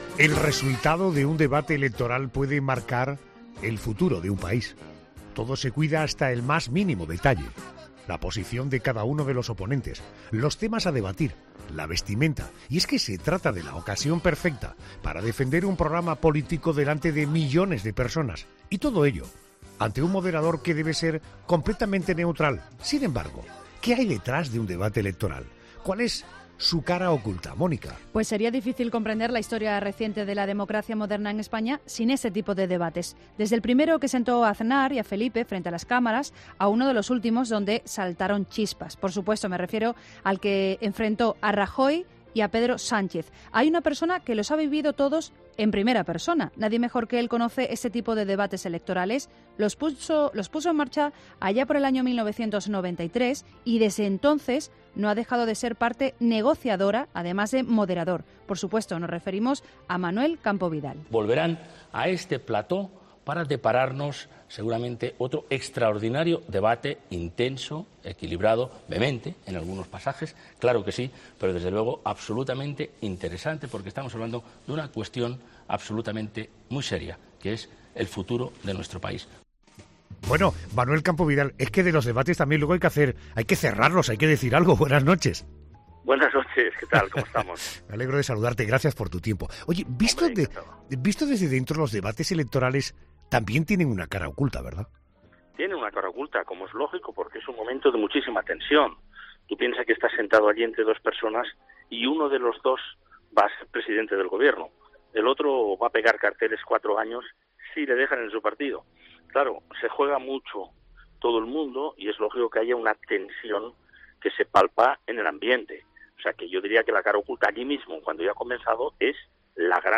ESCUCHA LA ENTREVISTA A MANUEL CAMPO VIDAL EN 'LA NOCHE'